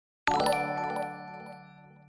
defaultogg-streamlabs-obs-notification-sound.mp3